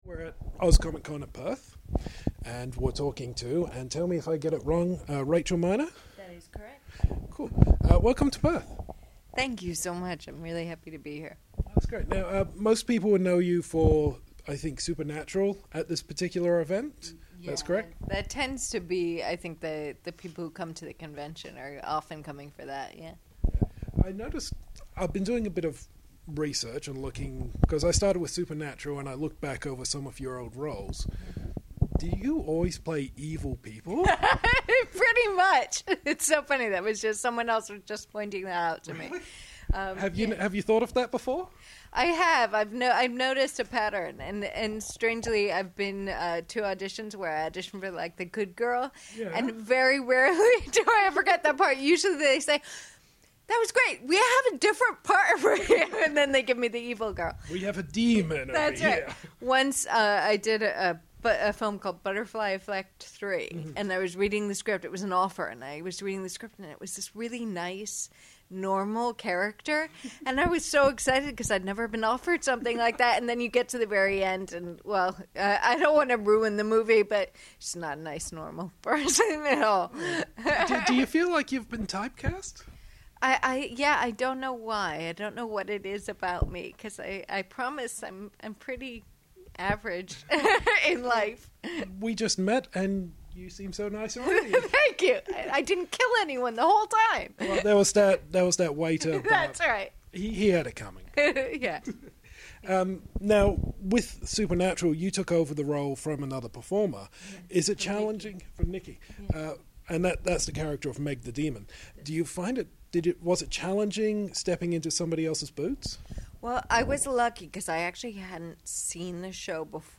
Exclusive Interview With Rachel Miner!
The first day of Perth’s Oz Comic-Con is done, and we have our next interview ready for you! Rachel Miner, demonic on Supernatural, manipulative in Californication and generally awesome joined us for a chat about playing evil, what she’s scared off and our new plan to get married!